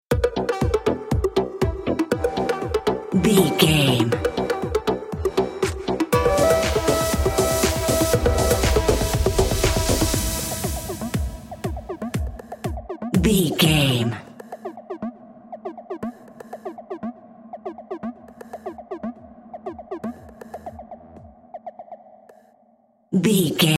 Top 40 Chart Electronic Dance Music 15 Sec.
Aeolian/Minor
groovy
dreamy
smooth
futuristic
driving
drum machine
synthesiser
house
techno
trance
instrumentals
synth leads
synth bass
upbeat